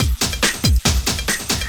04 LOOP09 -L.wav